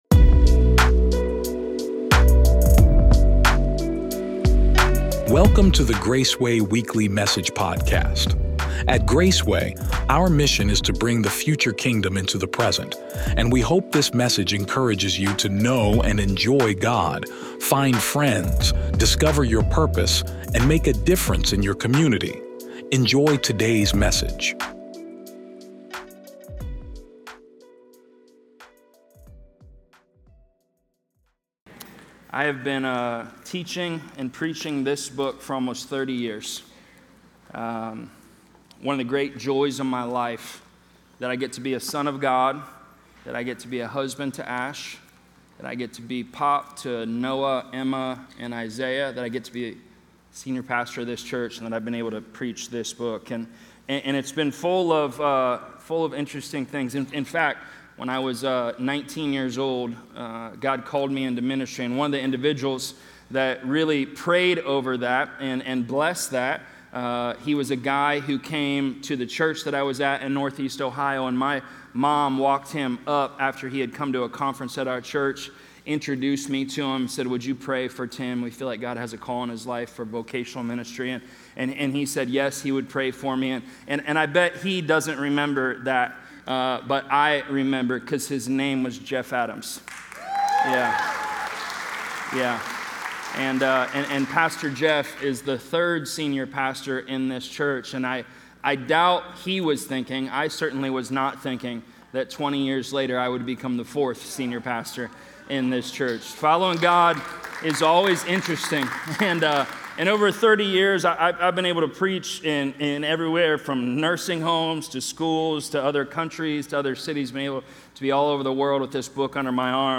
Graceway Sermon Audio Podcast